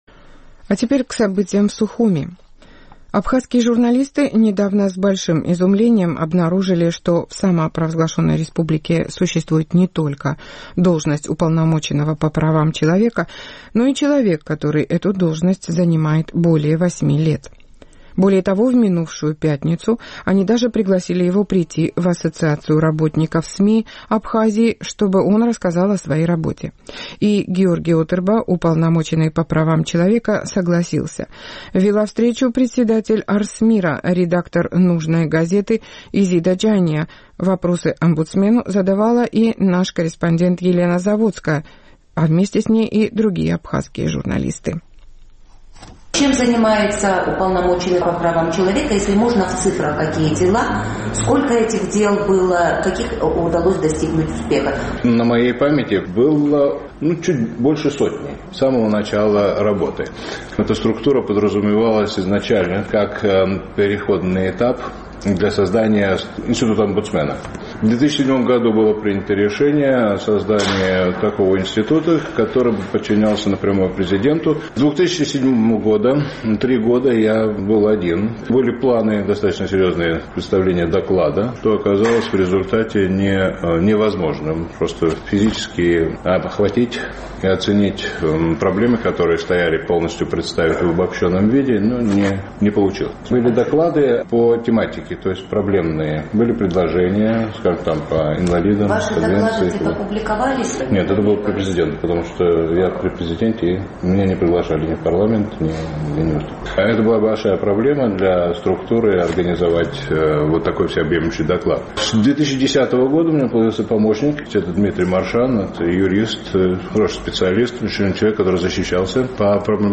Уполномоченный по правам человека Георгий Отырба на встрече в Ассоциации работников СМИ Абхазии рассказал о своей работе.